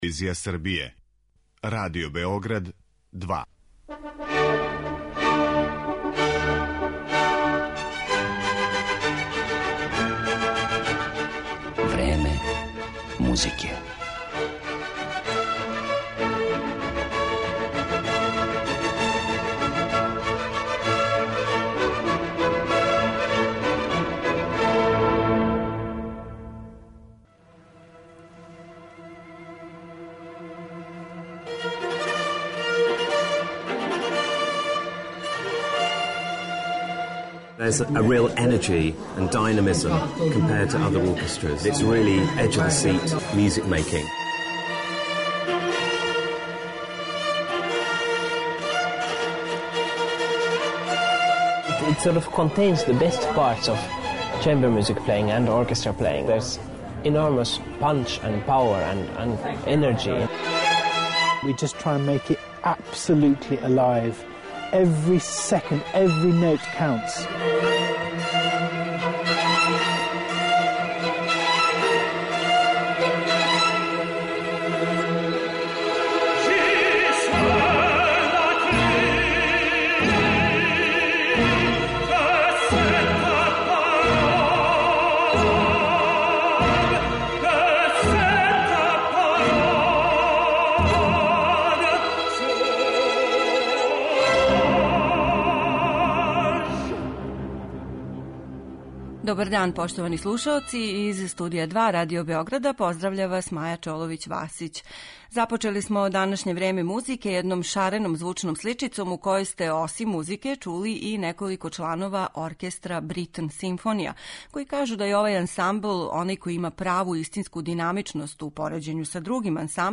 Емисија је посвећена камерном оркестру „Бритн симфонија”.
У данашњој емисији ћемо га представити избором композиција из реперотара који обухвата широки период од 400 година - од барока до XXI века.